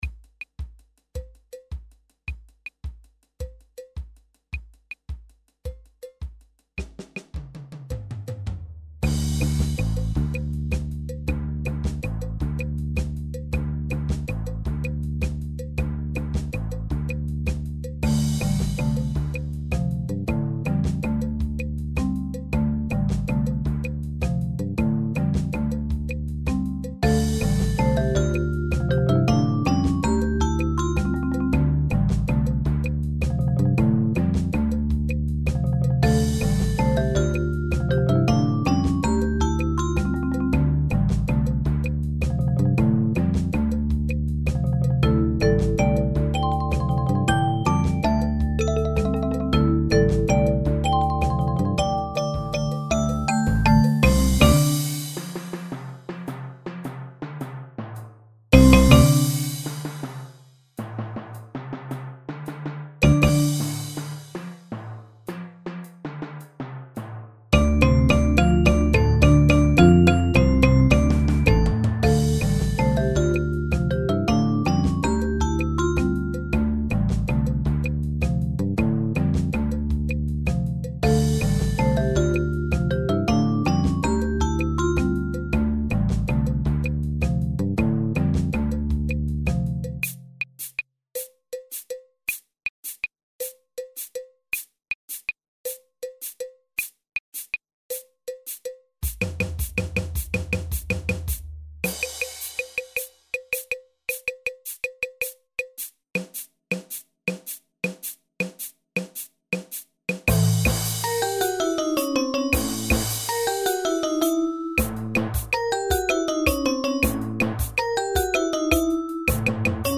Percussion-Ensemble mit 11 Stimmen